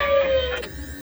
SERVO SE06.wav